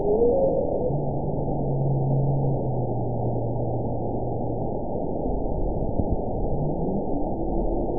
event 922398 date 12/30/24 time 21:58:13 GMT (4 months ago) score 9.23 location TSS-AB04 detected by nrw target species NRW annotations +NRW Spectrogram: Frequency (kHz) vs. Time (s) audio not available .wav